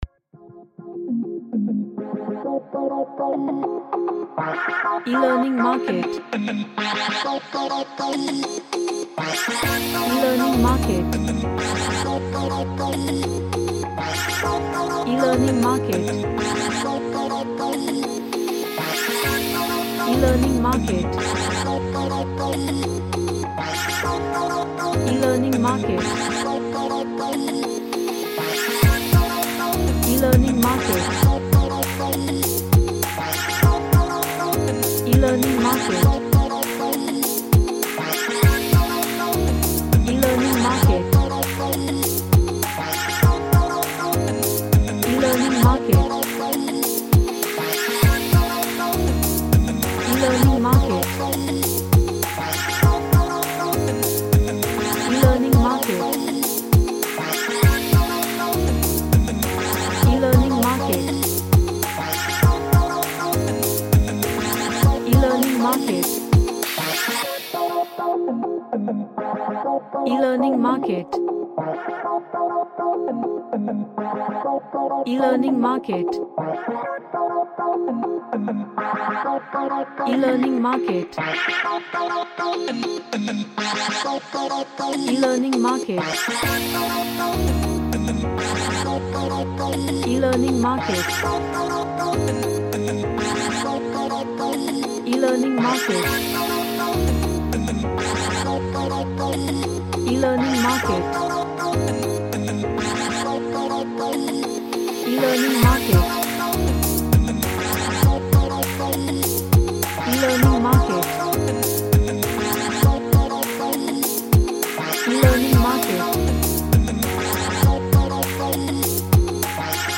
Vocodex on jungle drums
Sci-Fi / Future